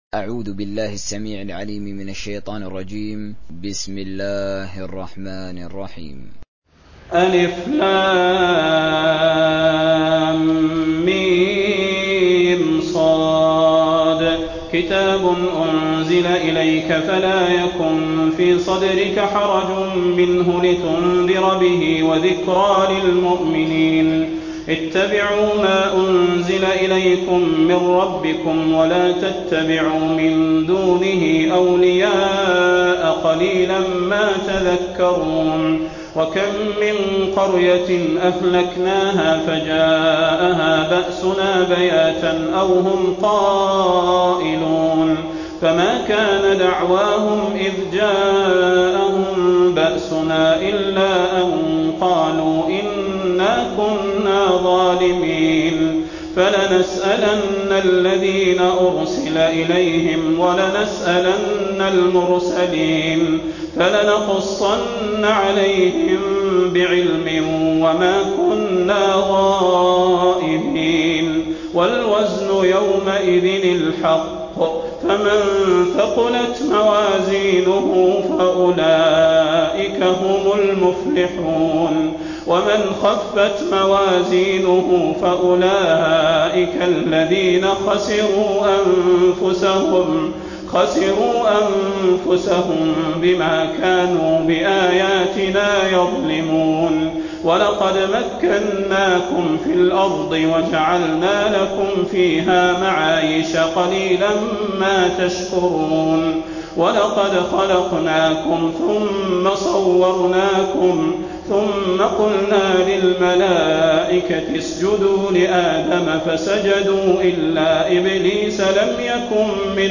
دانلود سوره الأعراف حسين آل الشيخ تراويح